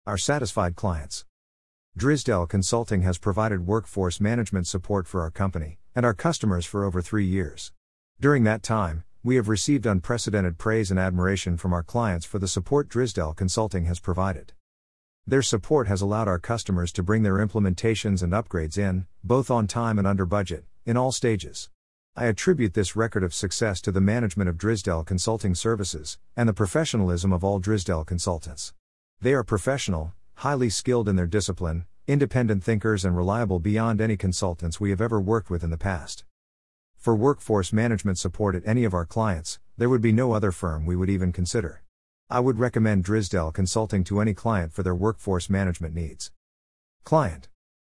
easytts_audio_Our-Satisfied-Clients1-1.mp3